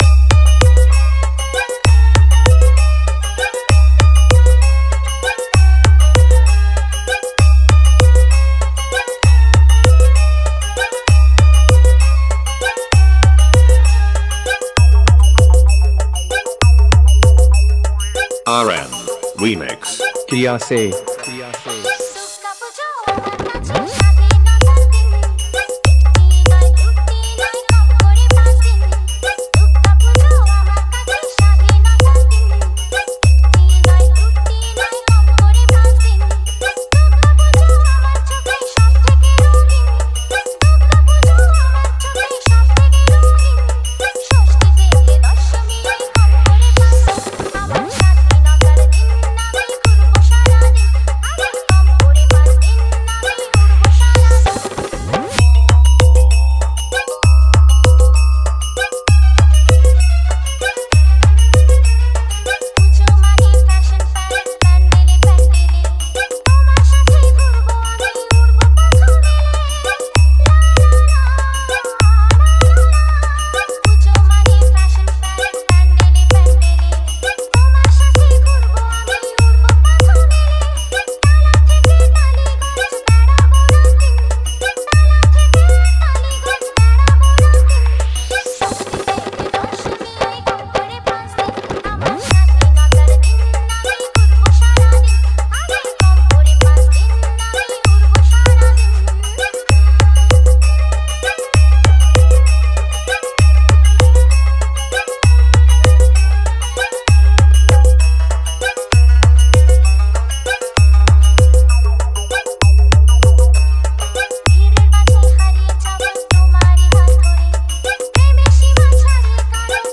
ভক্তি হামবিং মিক্স
দুর্গ উৎসব স্পেশাল বাংলা নতুন স্টাইল ভক্তি হামবিং মিক্স 2024